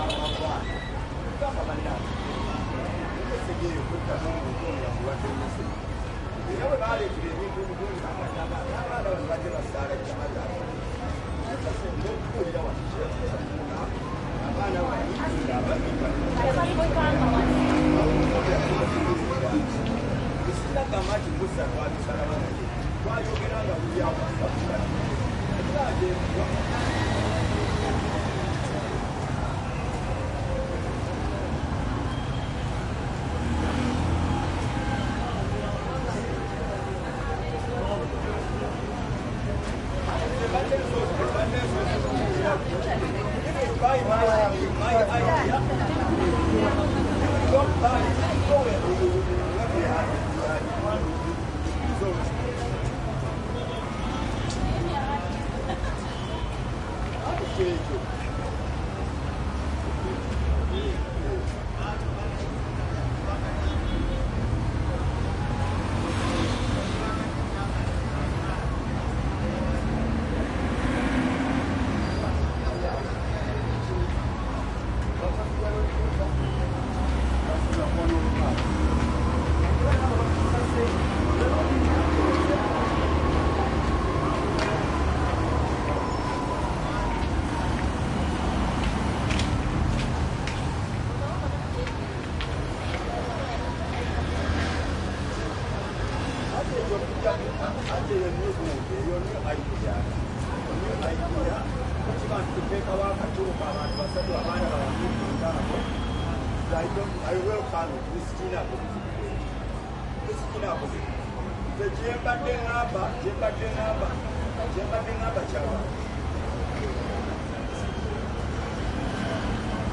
印度 " 市场内覆盖的人行道上，繁忙的街道上有袅袅的车流，柔和的音调和塔布拉小贩 印度
描述：市场int ext覆盖人行道上繁忙的街道与嘶哑的交通柔和的语气和tabla供应商India.flac
标签： 市场 人行道 覆盖 交通 街道 分机 印度 INT 沙哑
声道立体声